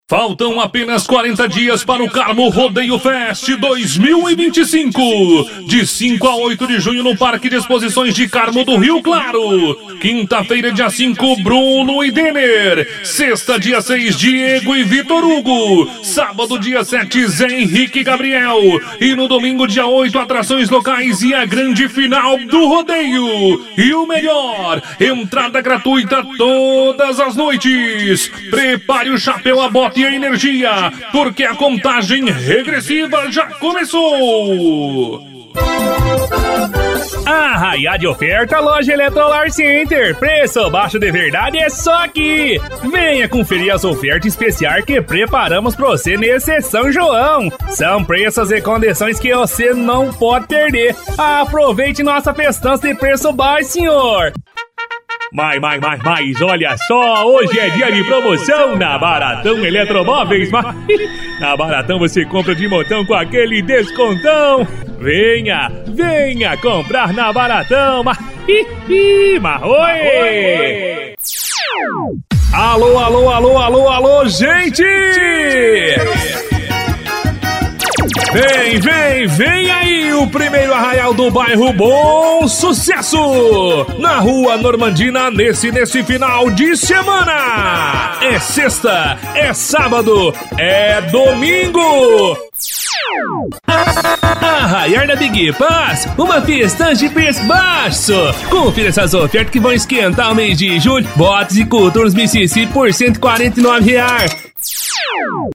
VOZ RODEIO - CAIPIRA - SILVIO SANTOS - AO VIVO:
Caricata